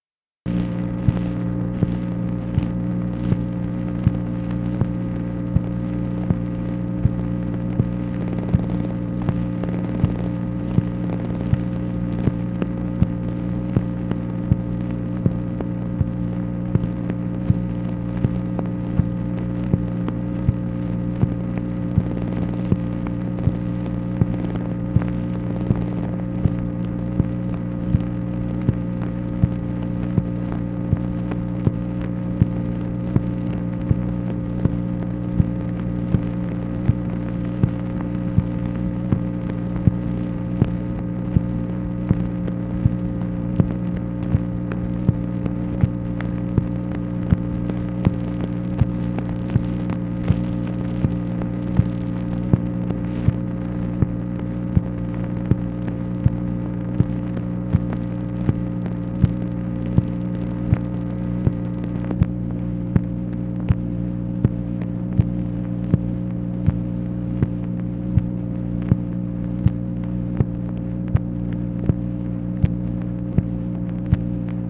MACHINE NOISE, December 20, 1966
Secret White House Tapes | Lyndon B. Johnson Presidency